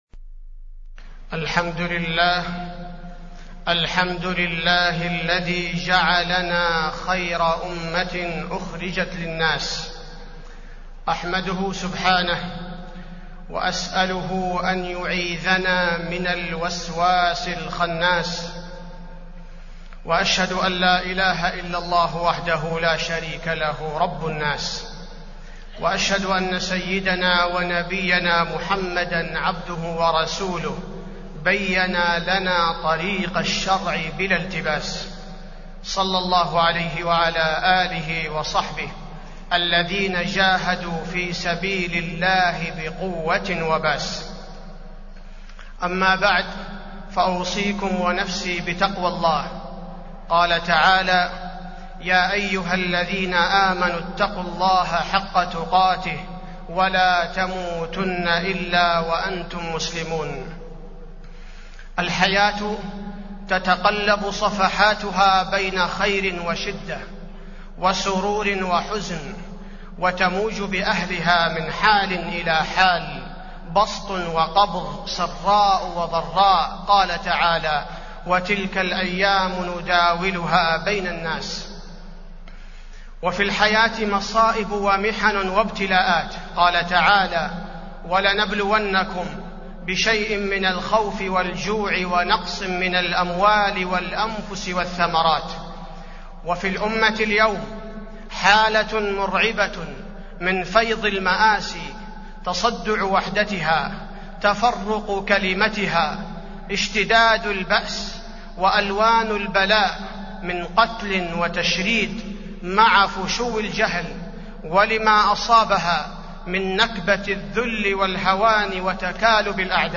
تاريخ النشر ١٨ جمادى الآخرة ١٤٢٧ هـ المكان: المسجد النبوي الشيخ: فضيلة الشيخ عبدالباري الثبيتي فضيلة الشيخ عبدالباري الثبيتي التفاؤل في الأزمات